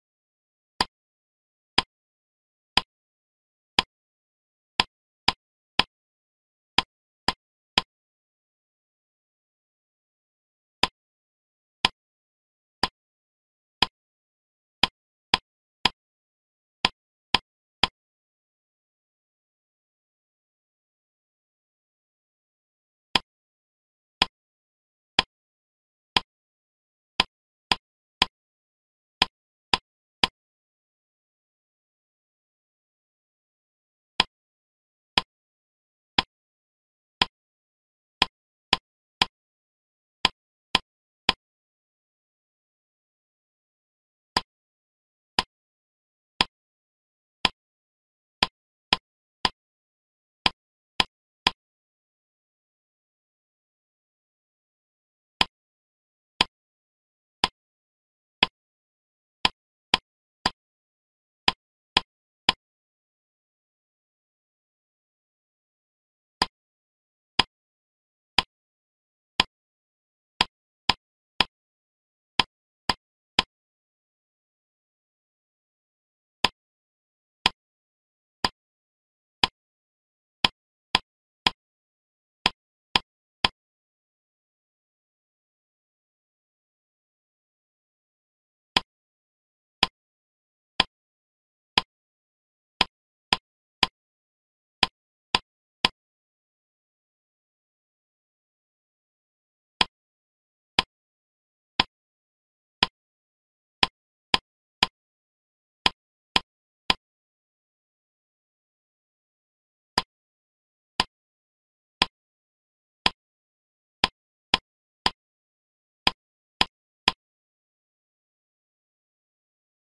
Tiếng gõ nhạc cụ Song loan mp3, âm thanh tiếng gõ nhạc cụ Song Lang gõ theo tiết tấu file mp3 chất lượng cao.
Tiếng gõ Song loan theo tiết tấu
tieng-go-song-loan-theo-tiet-tau-www_tiengdong_com.mp3